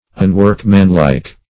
unworkmanlike - definition of unworkmanlike - synonyms, pronunciation, spelling from Free Dictionary